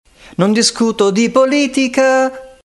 A vocoder was used on “pacifico” and then on “politica”, you can hear pre and post-treatment here. A full organ+string sound was used as the carrier to give the herbal voice.
pacifico-vocod.mp3